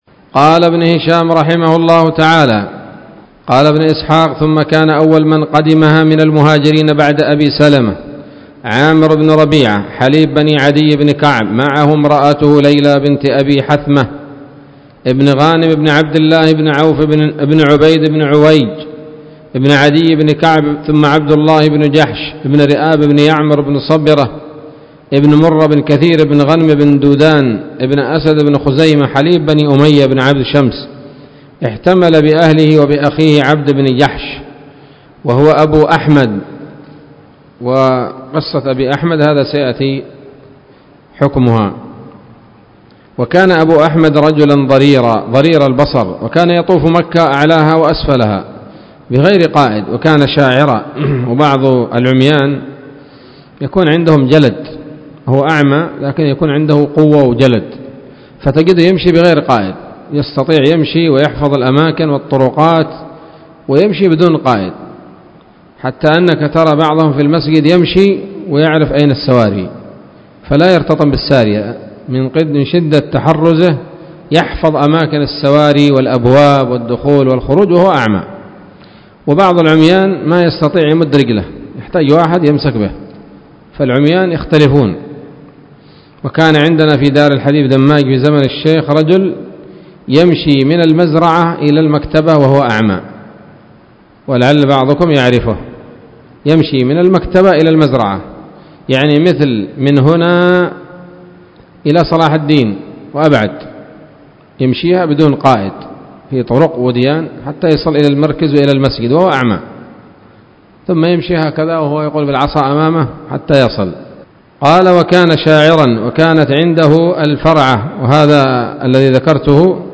الدرس التاسع والستون من التعليق على كتاب السيرة النبوية لابن هشام